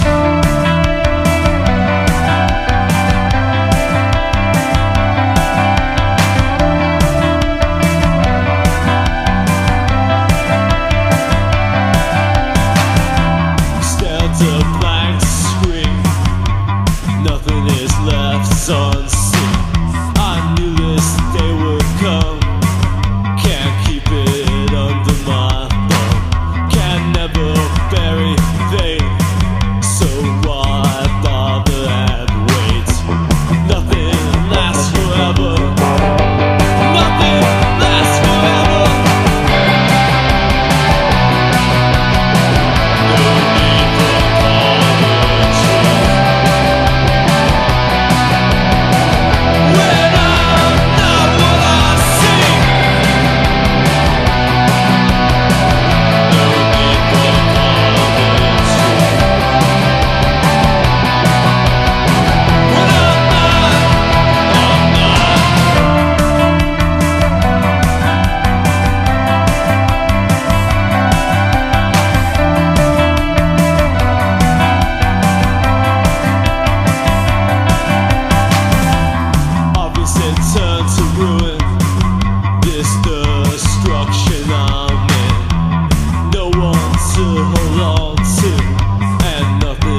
¥1,180 (税込) ROCK / 80'S/NEW WAVE. / FUNK-A-LATINA